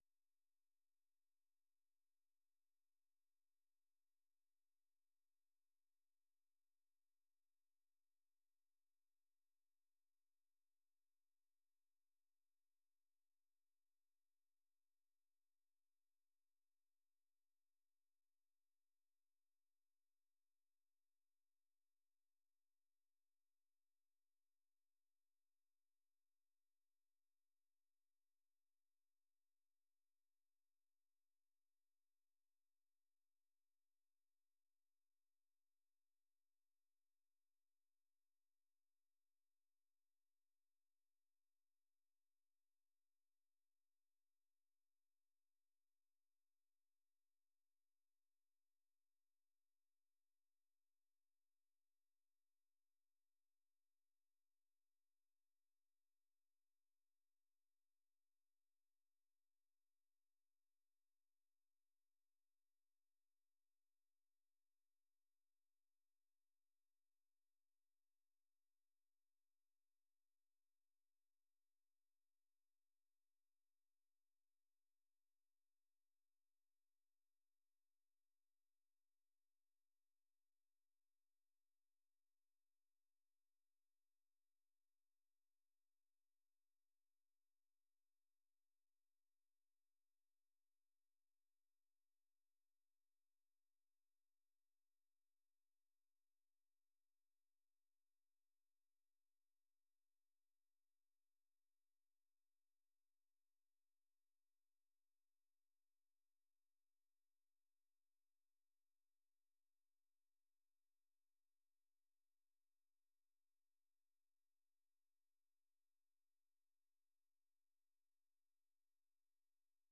အပြည်ပြည်ဆိုင်ရာ စံတော်ချိန် ၂၃၃၀ ၊ မြန်မာစံတော်ချိန် နံနက် ၆ နာရီကနေ ၇ နာရီထိ (၁) နာရီကြာ ထုတ်လွှင့်နေတဲ့ ဒီ ရေဒီယိုအစီအစဉ်မှာ မြန်မာ၊ ဒေသတွင်းနဲ့ နိုင်ငံတကာ သတင်းနဲ့ သတင်းဆောင်းပါးတွေ သီတင်းပတ်စဉ်ကဏ္ဍတွေကို နားဆင်နိုင်ပါတယ်။